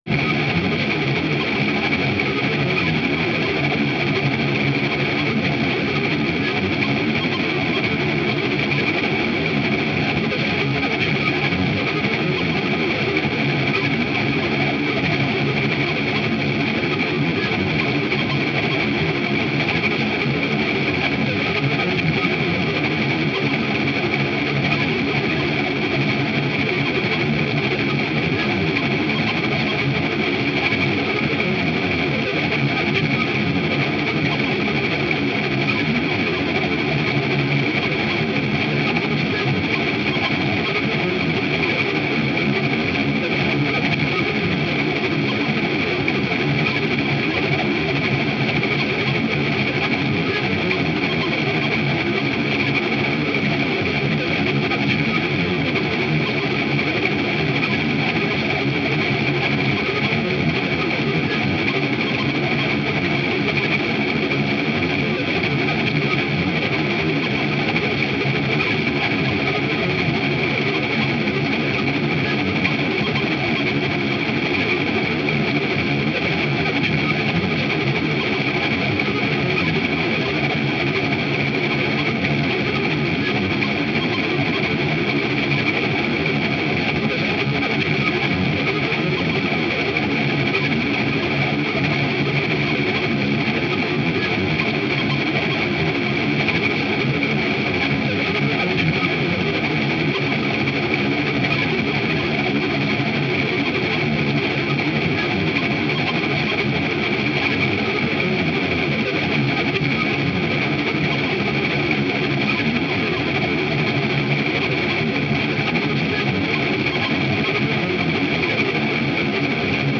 久々のギターらしいギターの音が聴けるアルバムです。
作曲されたオーケストレーション作品です。
超音波帯域の無数の細かな鋭い針が、脳に突き刺さり続ける。
轟音と静寂、激しさと安らかさ、覚醒と酩酊が同時にそこに在るような。